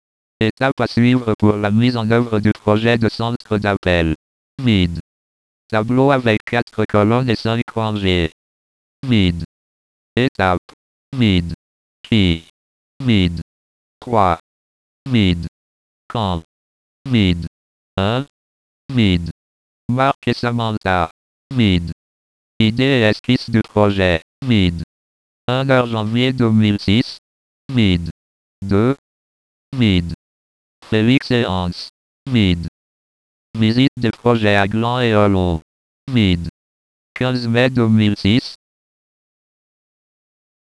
(wav, 876 Ko) Ecouter le logiciel de lecture d'écran lire ce tableau copié-collé depuis un traitement de texte